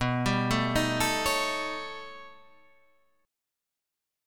B+9 Chord
Listen to B+9 strummed